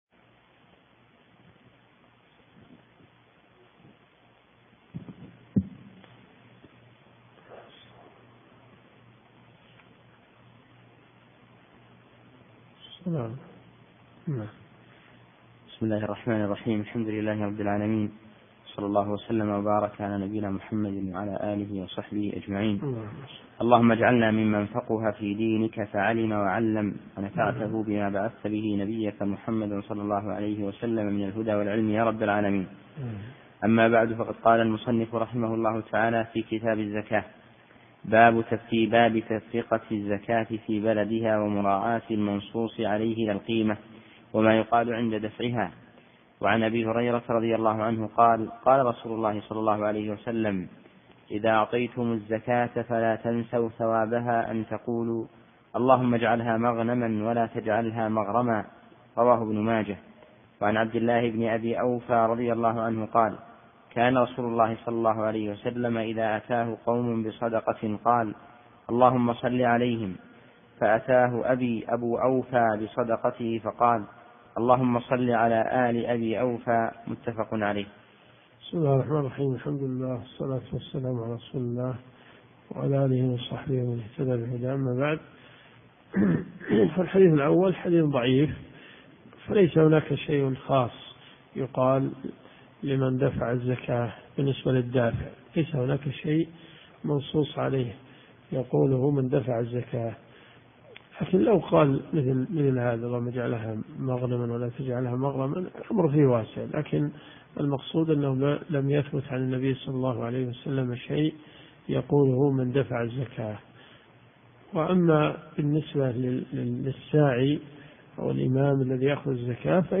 دورة صيفية في مسجد معاذ بن جبل .